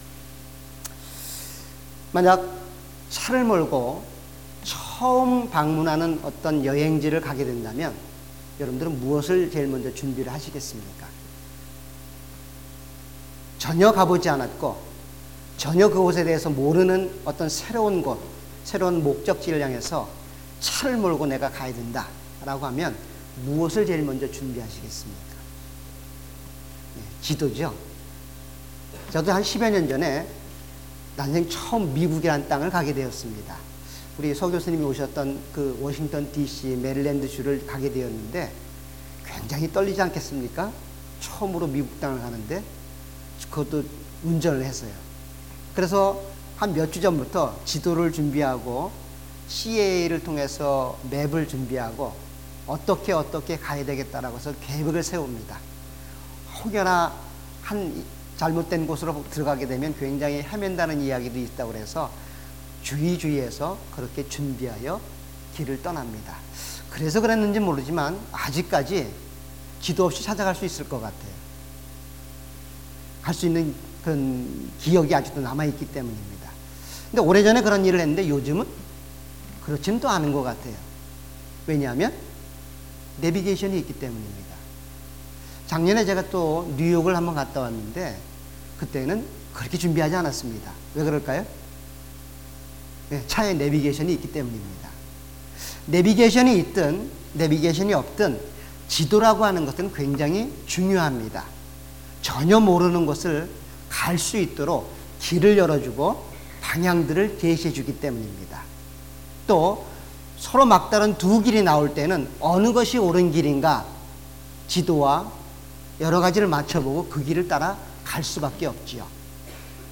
All Sermons
Series: 주일예배.Sunday